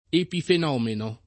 epifenomeno [ epifen 0 meno ]